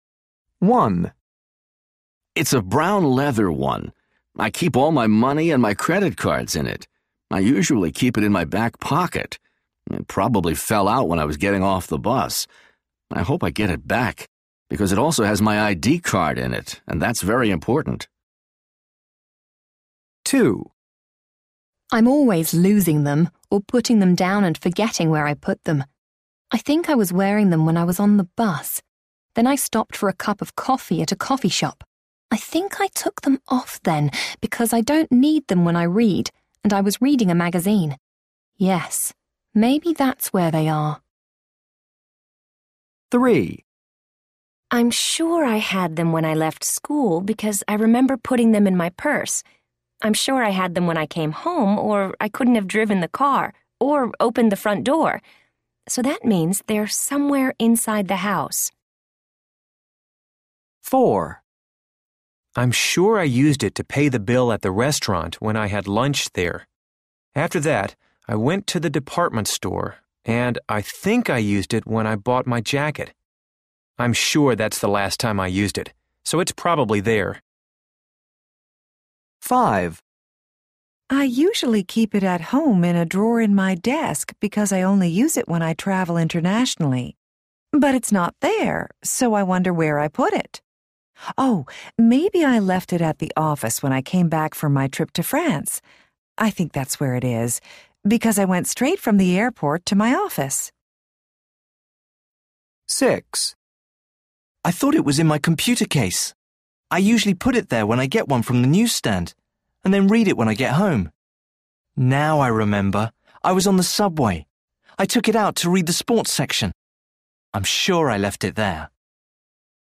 A. People are talking about items they lost.